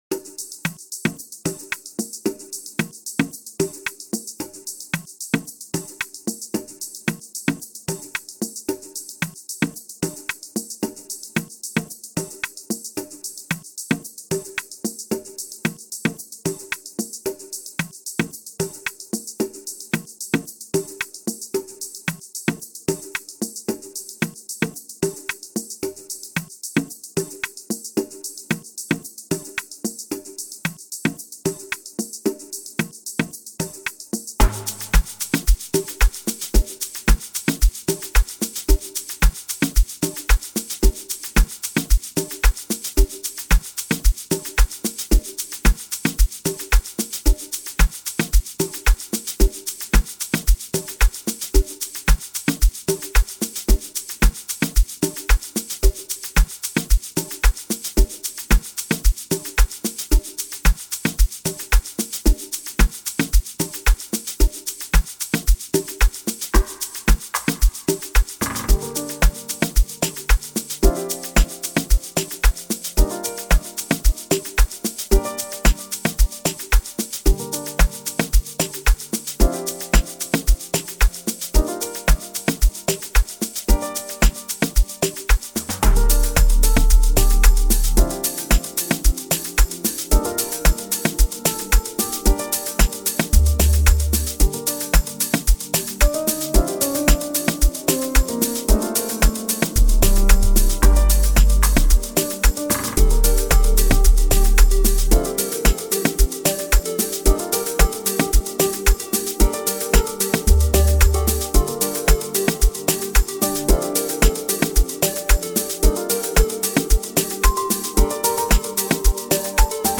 Find more Amapiano Songs on Amapiano Updates .